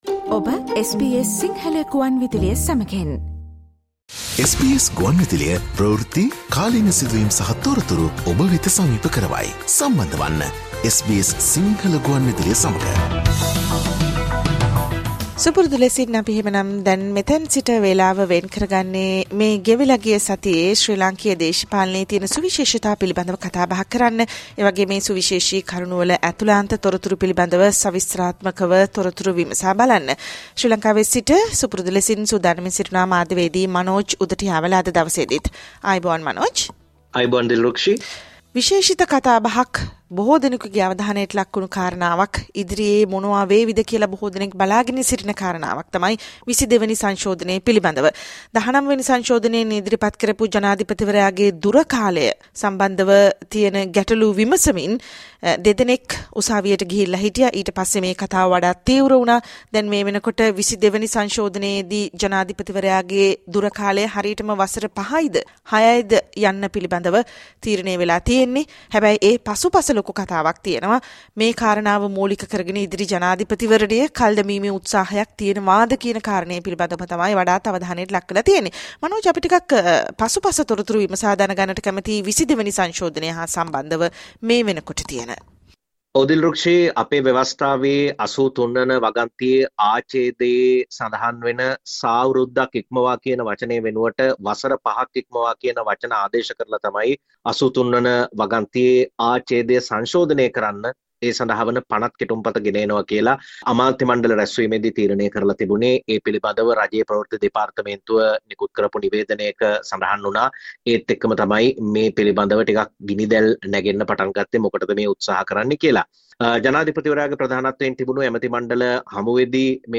Listen to Sri Lanka’s weekly political highlights. Journalist - news, and current affairs